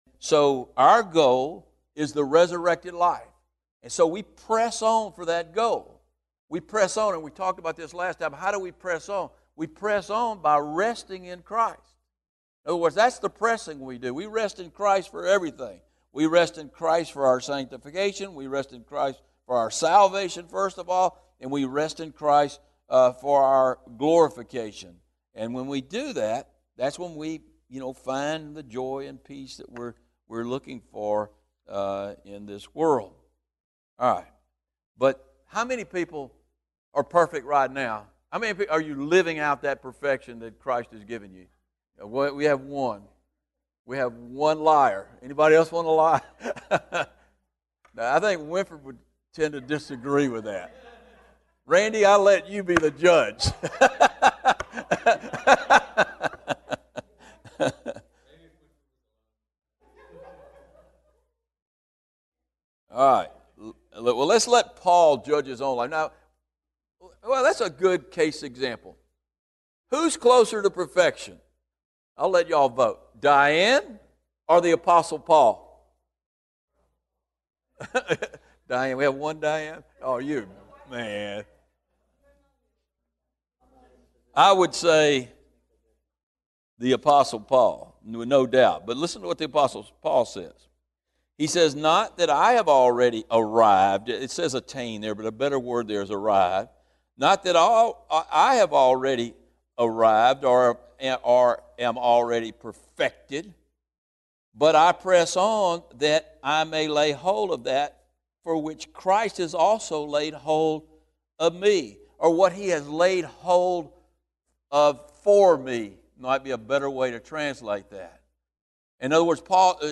These teachings on Philippians are from Wednesday evening service.